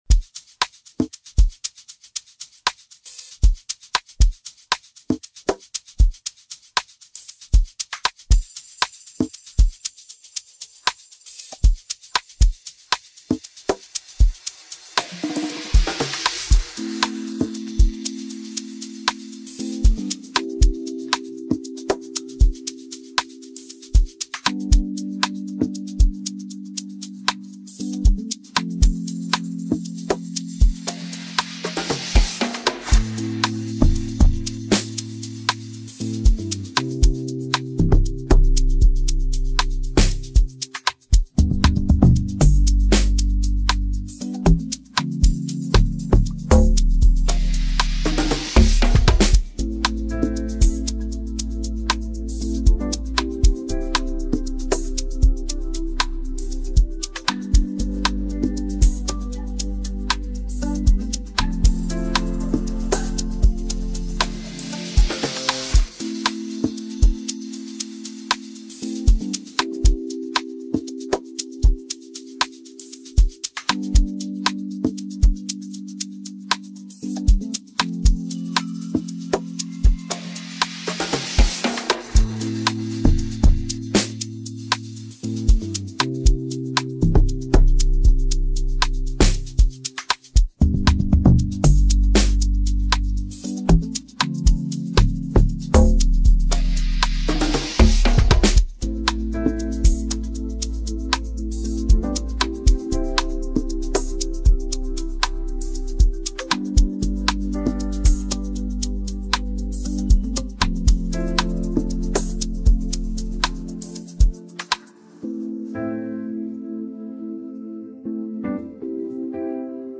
This is the instrumental of the new song.